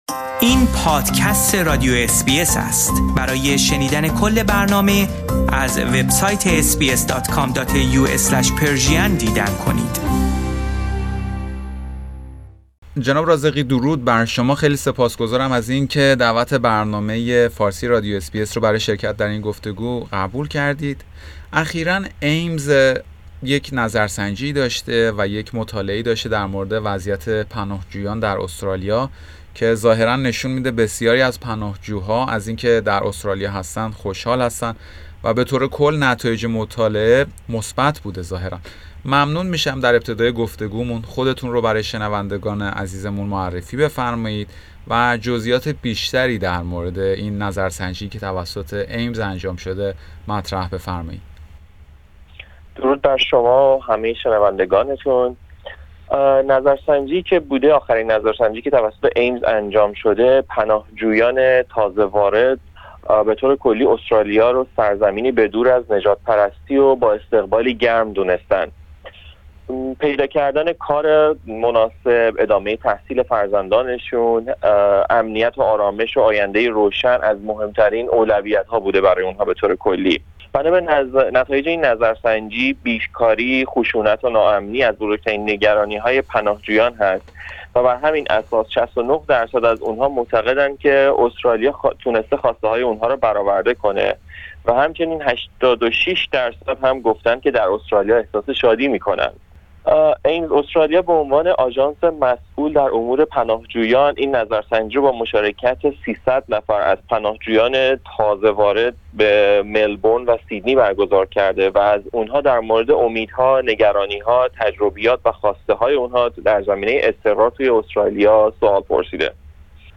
در گفتگو با برنامه فارسی رادیو SBS